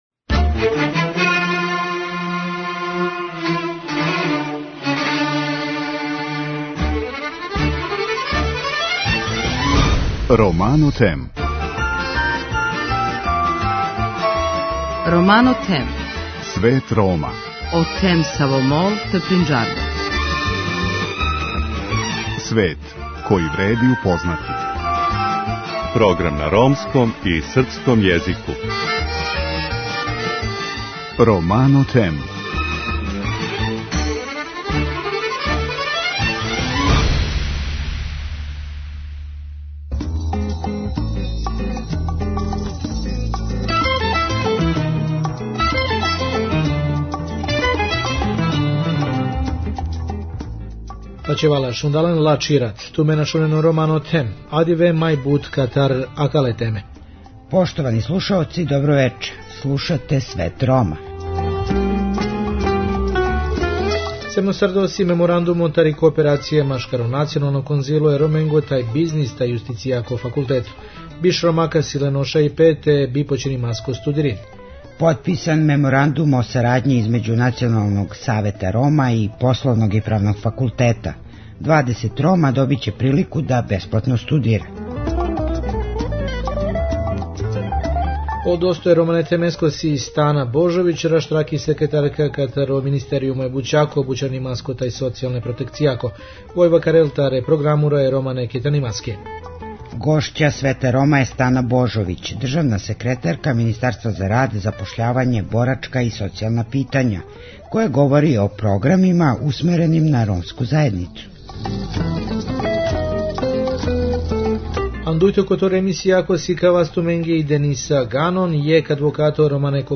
Гошћа Света Рома је Стана Божовић, државна секретарка Министарства за рад, запошљавање, борачка и социјална питања која говори о програмима усмереним на ромску заједницу.